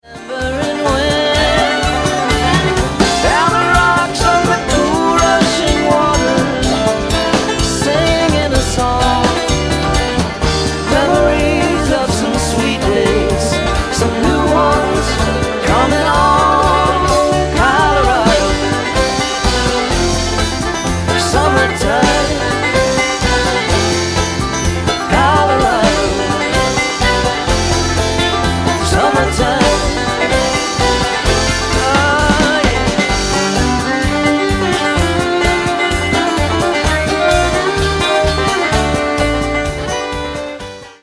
BLUEGRASS/JAM